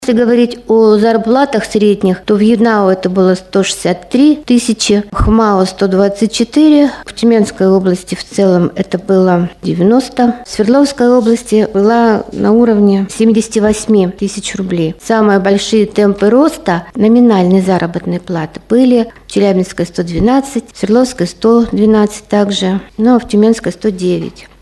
на пресс-конференции «ТАСС-Урал».